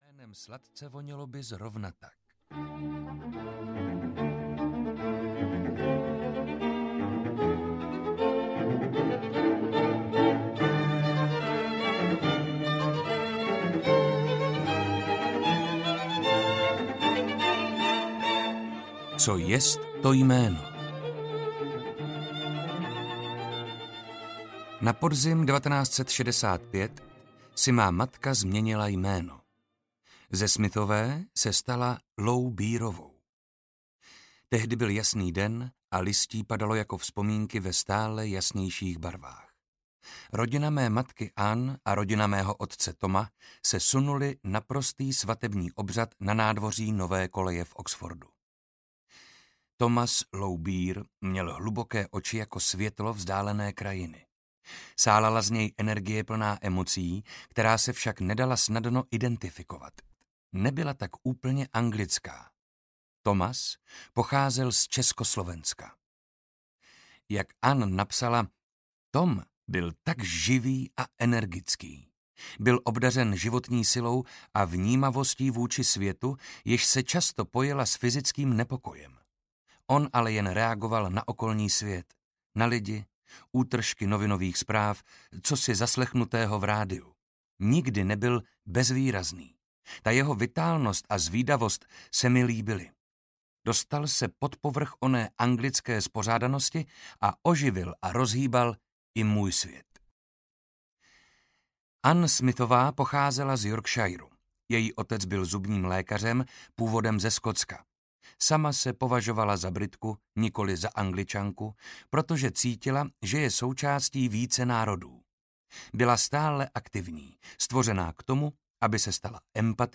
Archy života audiokniha
Ukázka z knihy
• InterpretMarek Holý